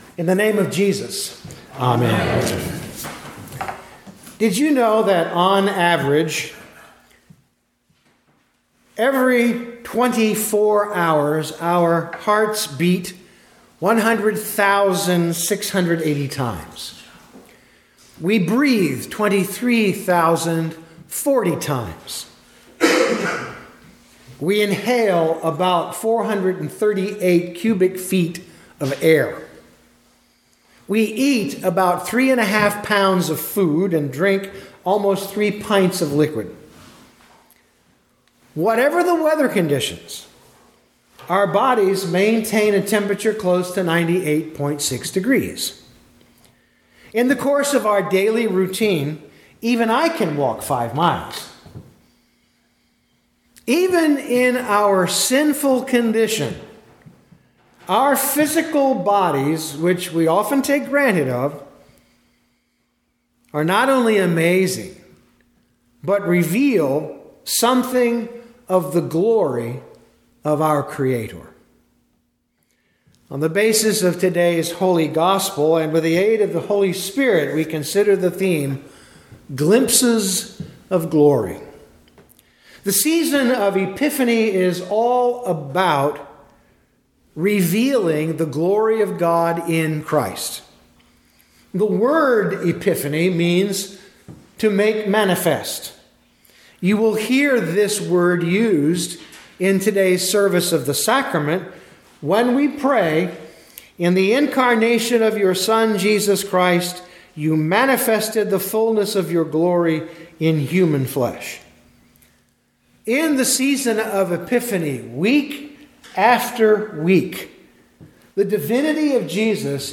2025 John 2:1-11 Listen to the sermon with the player below, or, download the audio.